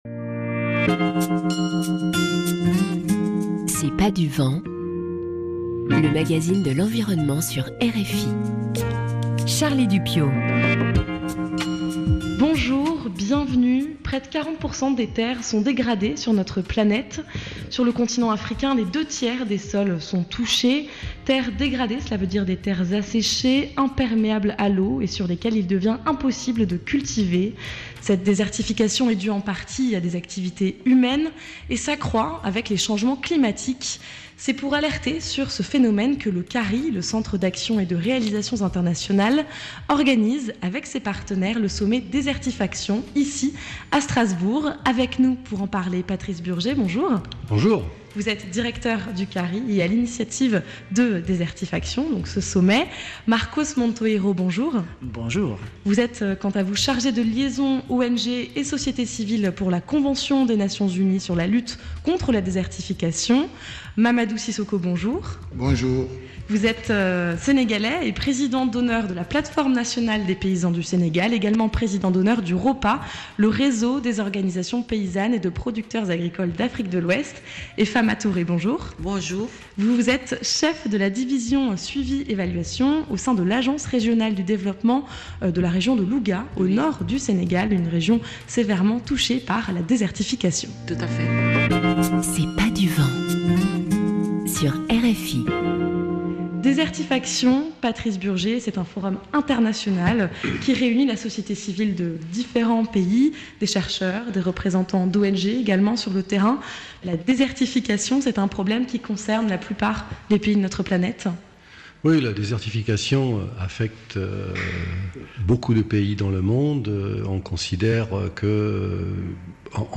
Transcription de l'audio RFI – Chronique C’est pas du vent , du 29 juin 2017 « 2.